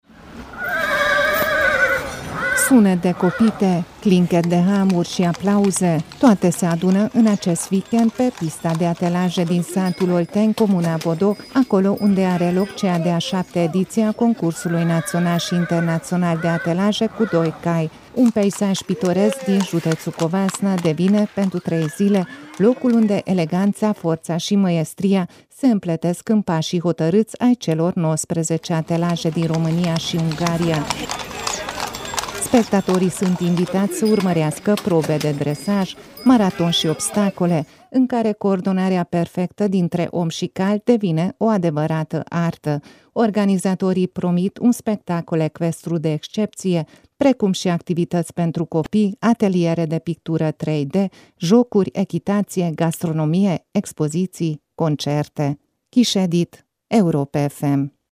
Sunet de copite, clinchet de hamuri și aplauze – toate se adună, în acest weekend (între 13 și 15 iunie), pe pista de atelaje din satul Olteni, comuna Bodoc, acolo unde are loc cea de-a VII-a ediție a Concursului Național și Internațional de Atelaje cu Doi Cai.